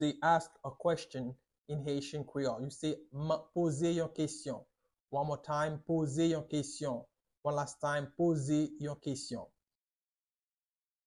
Pronunciation:
4.How-to-say-Ask-a-Question-in-Haitian-Creole-–-Poze-yon-kesyon-with-pronunciation.mp3